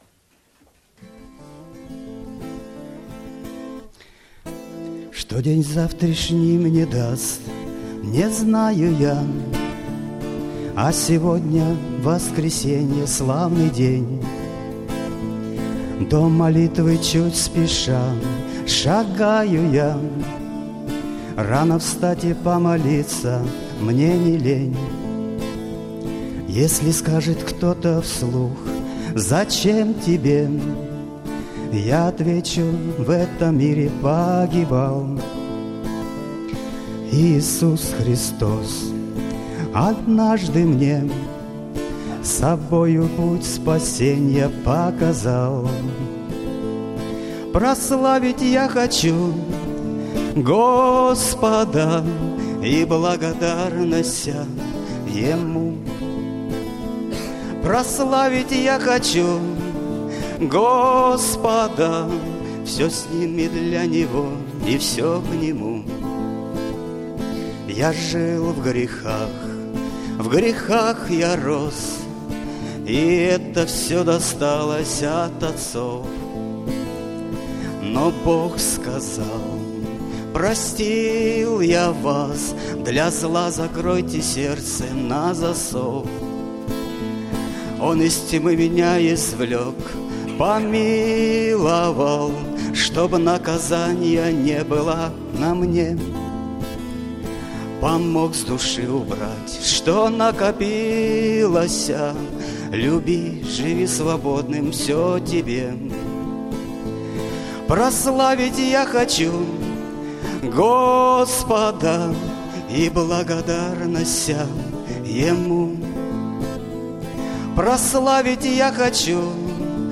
Богослужение 12.09.2010 mp3 видео фото
Пение